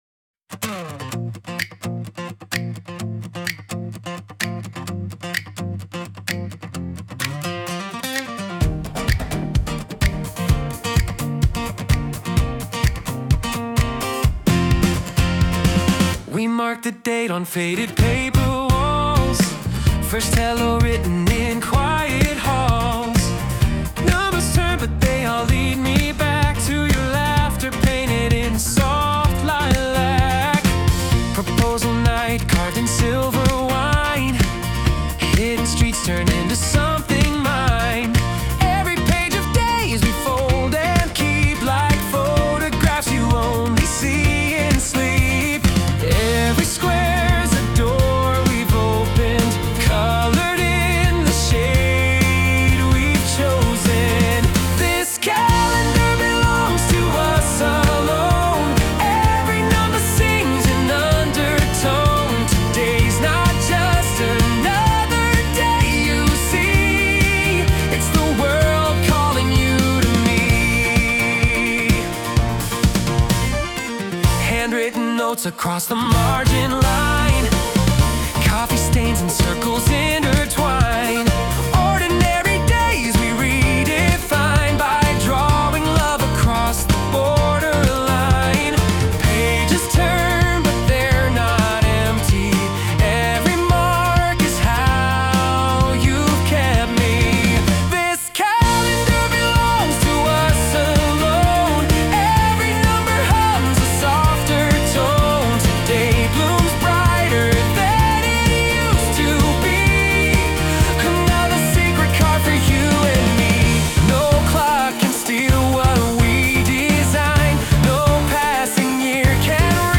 洋楽男性ボーカル著作権フリーBGM ボーカル
著作権フリーオリジナルBGMです。
男性ボーカル（洋楽・英語）曲です。